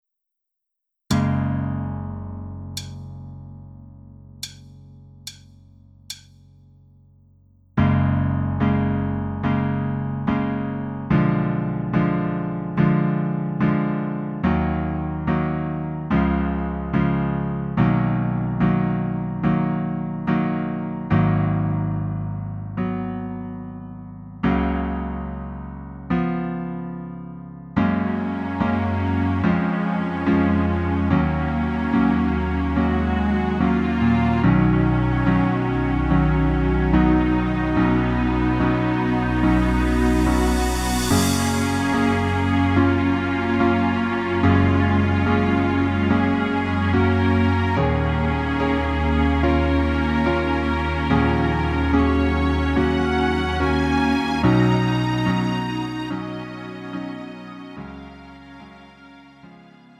음정 -1키 3:51
장르 가요 구분